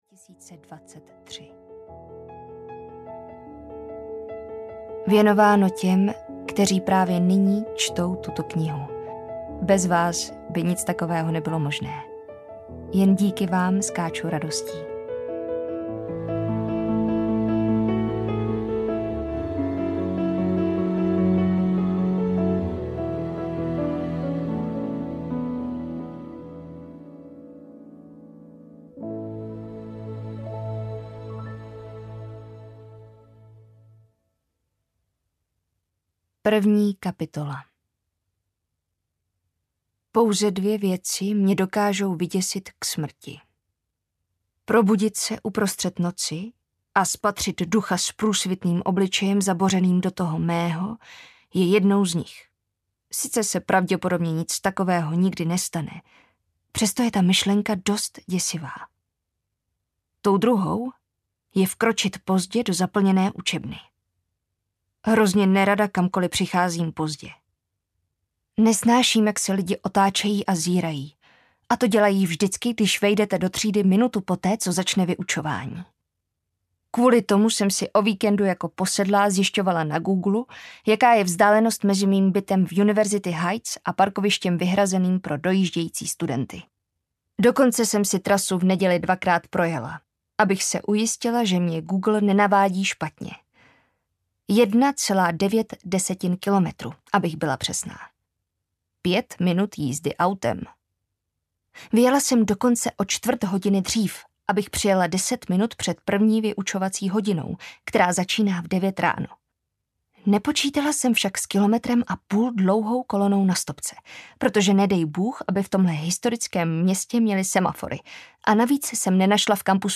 Počkám na Tebe audiokniha
Ukázka z knihy